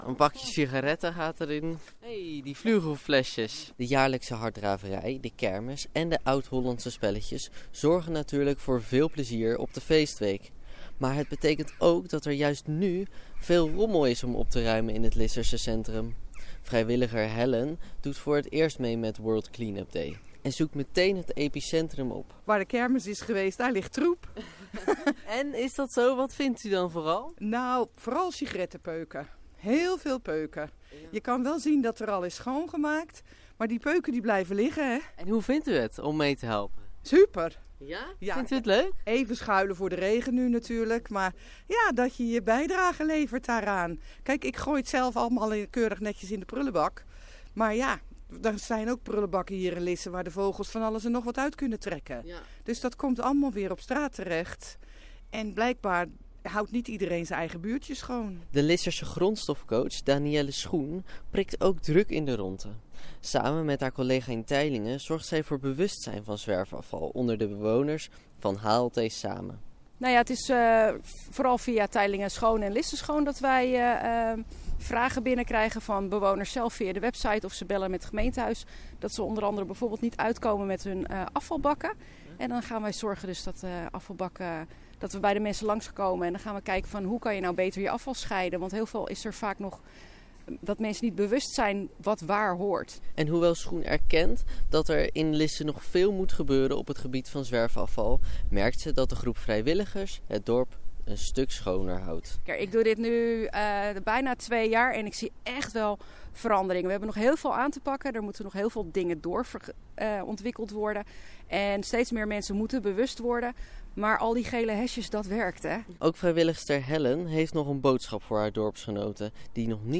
radioreportage